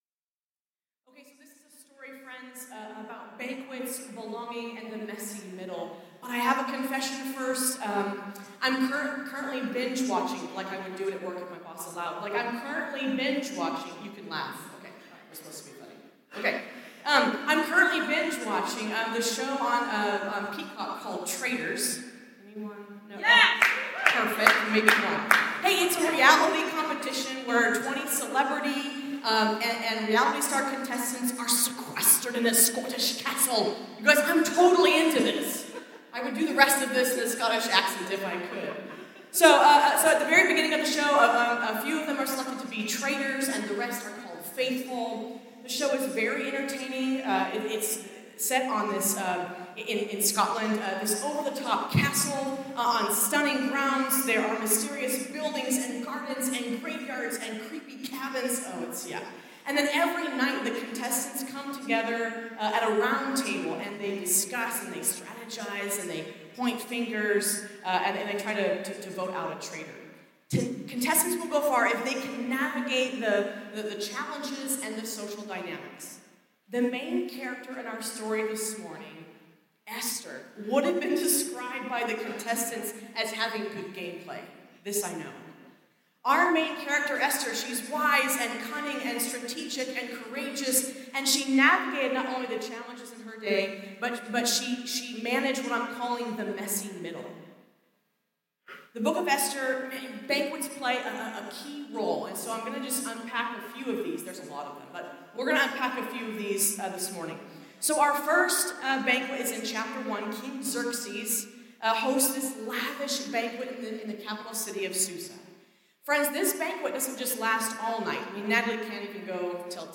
This talk was given in chapel on Friday, March 21st, 2025 God Bless you.